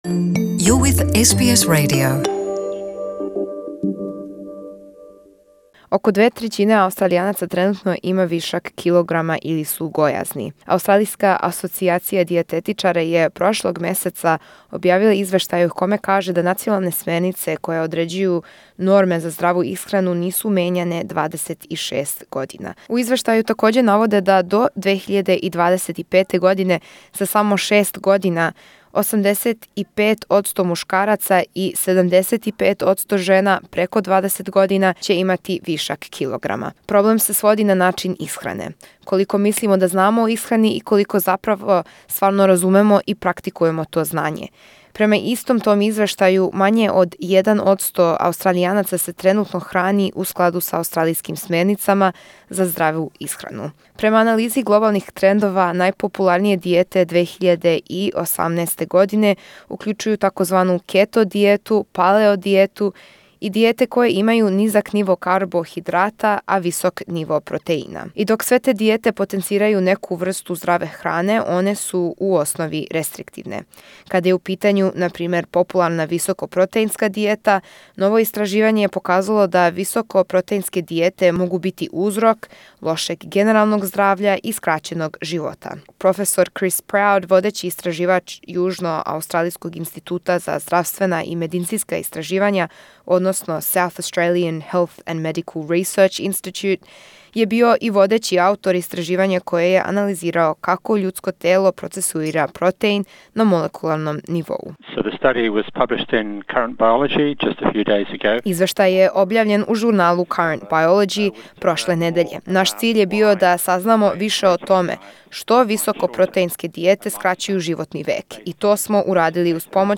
разговарала са стручњацима